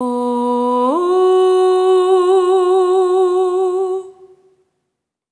SOP5TH B3 -L.wav